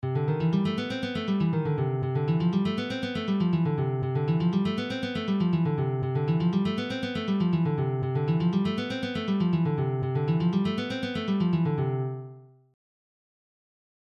Exercises > C Major Exersice
C+Major+Exersice.mp3